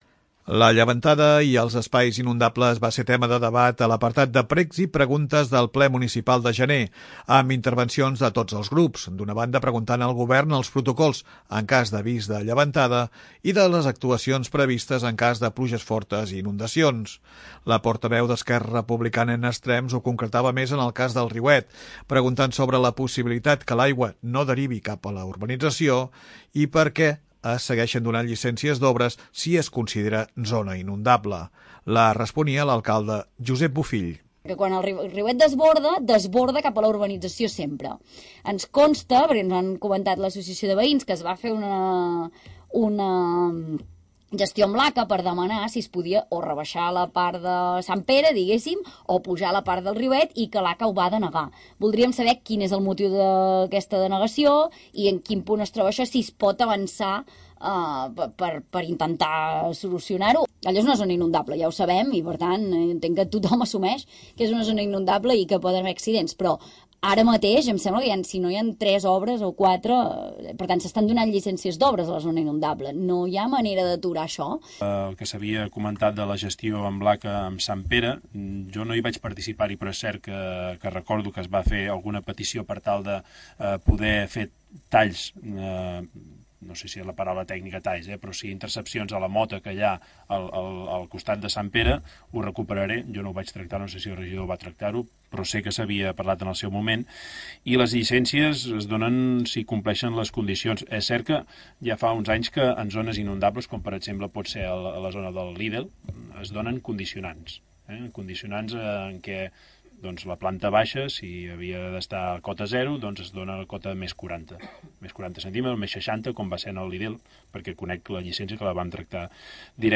La llevantada i els espais inundables van ser tema de debat a l'apartat de precs i preguntes del ple municipal de gener, amb intervencions de tots els grups.